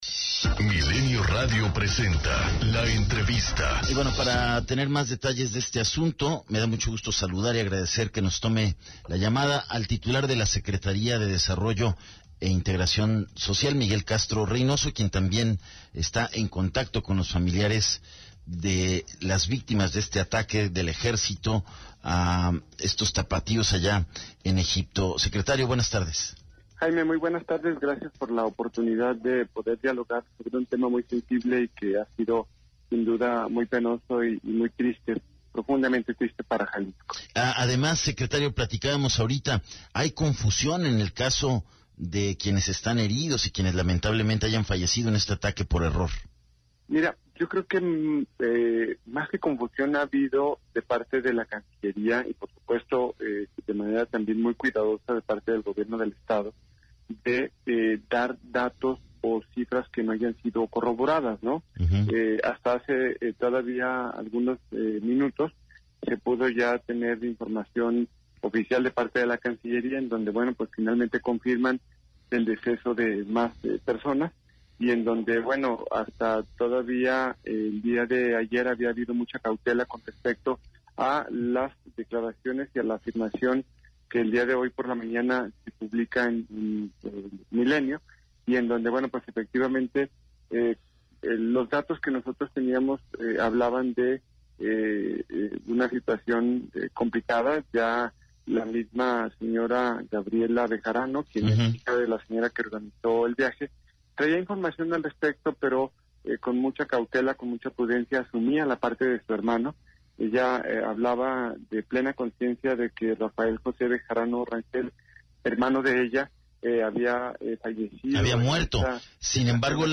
ENTREVISTA 150915
El Secretario de Desarrollo e Integración Social, Miguel Castro Reynoso, afirmó en entrevista para Milenio Radio que se les dará todo el apoyo a los familiares de las víctimas del atentado en Egipto